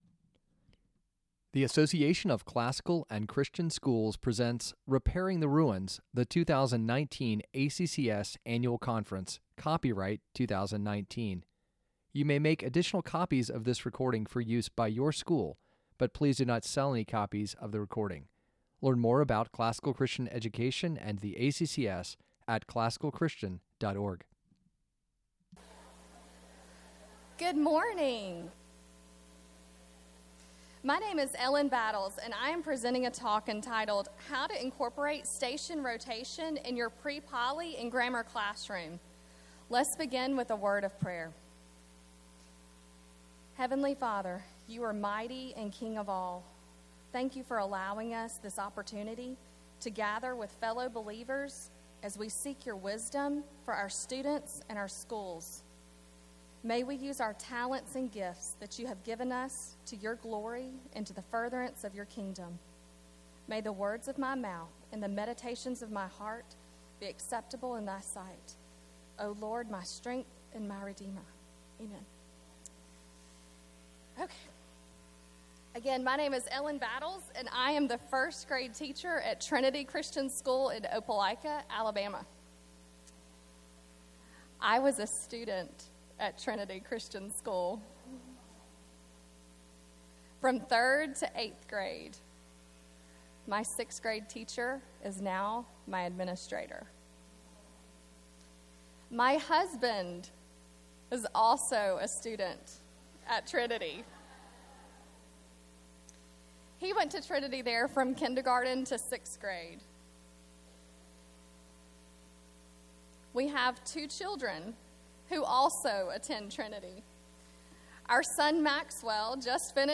2019 Workshop Talk | 01:03:07 | K-6, Teacher & Classroom